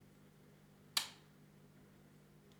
Light switch on
light switch on.WAV